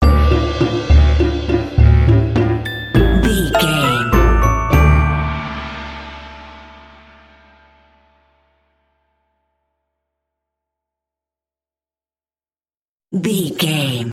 Uplifting
Dorian
percussion
flutes
piano
orchestra
double bass
goofy
comical
cheerful
Light hearted
quirky